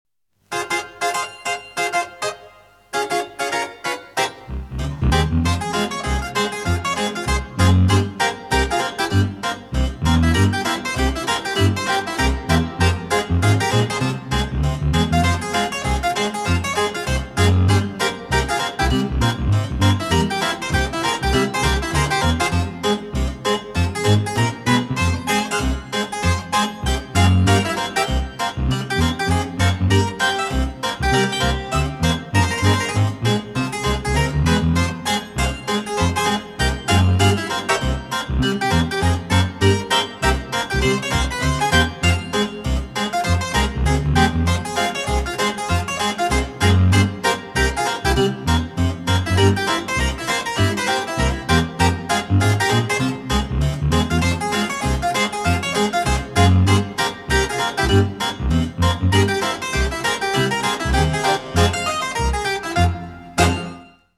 • Качество: 320, Stereo
красивые
веселые
спокойные
без слов
инструментальные